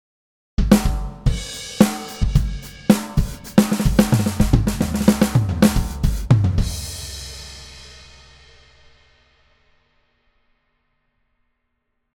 VQ Drums_After
VQ-Drums_After.mp3